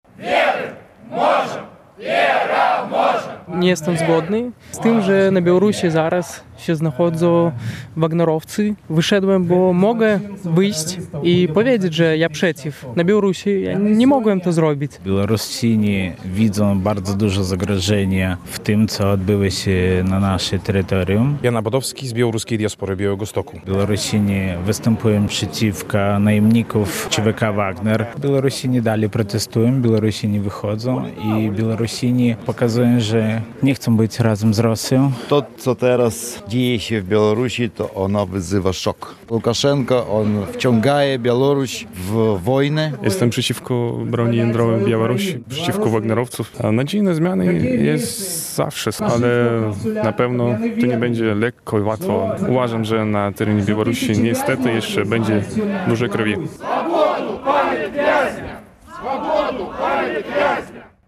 "Razem przeciw zagrożeniom dla suwerenności Białorusi” - pikieta pod konsulatem w Białymstoku